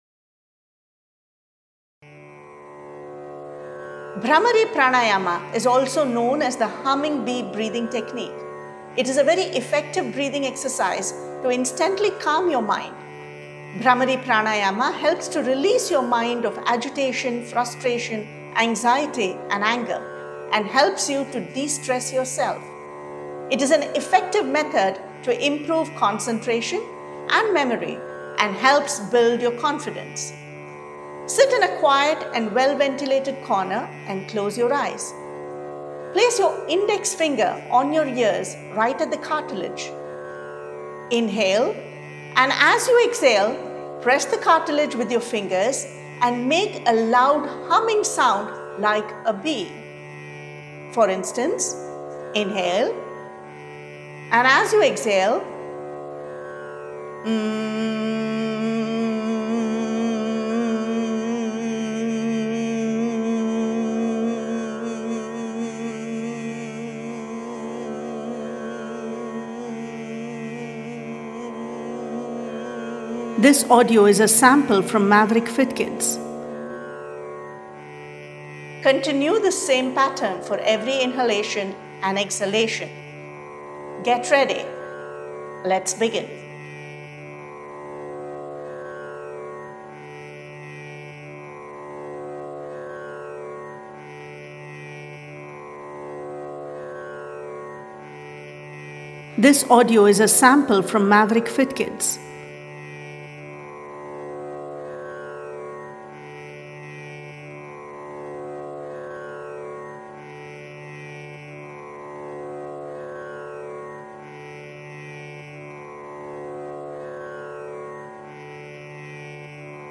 Brahmari-instructions-sample.mp3